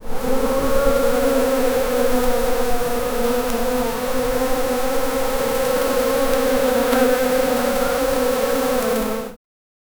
Hissing smoke, frantic bee swarms 0:05 Created Mar 16, 2025 5:13 AM Weak but defiant roar of the tiger, bees scattering in panic, and echoes bouncing off distant acacia trees. 0:10 Created Mar 17, 2025 10:48 AM Swarm of bees approaching 0:11 Created Sep 6, 2024 12:03 AM
weak-but-defiant-roar-of-f4vuldbl.wav